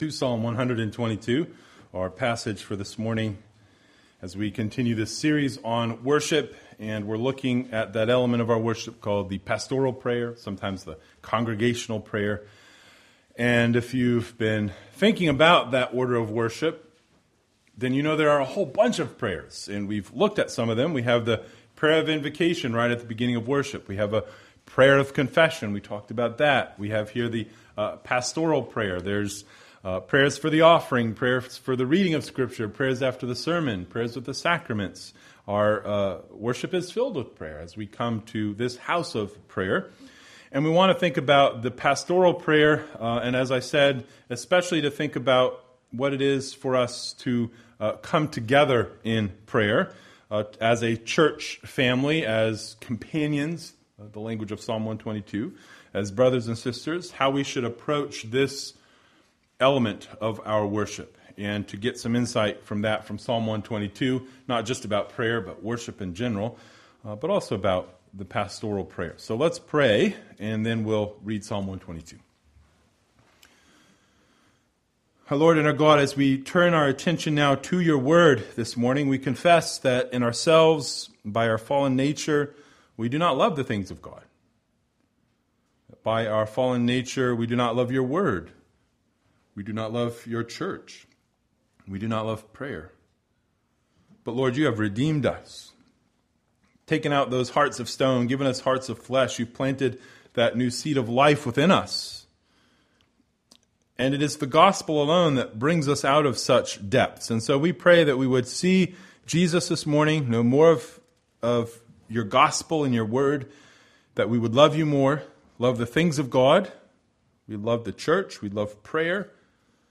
Pastoral Prayer – Staunton Orthodox Presbyterian Church
Pastoral Prayer
Pastoral-Prayer.mp3